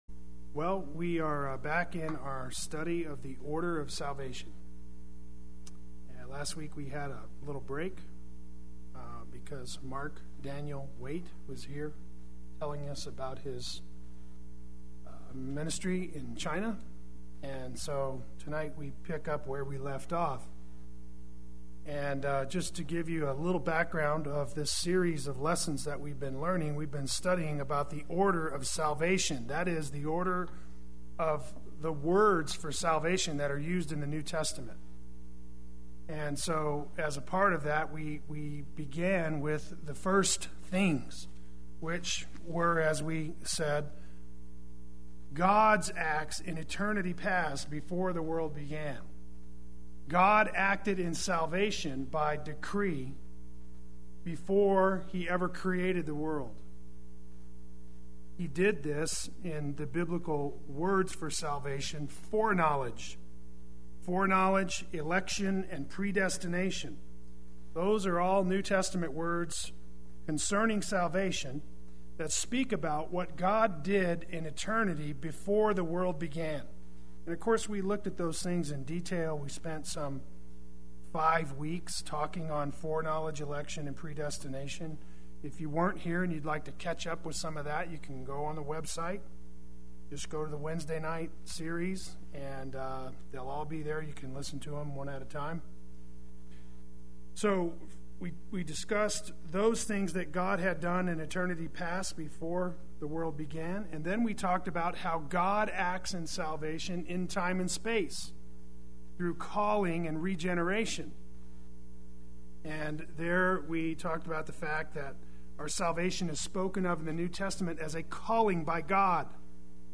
Play Sermon Get HCF Teaching Automatically.
Wednesday Worship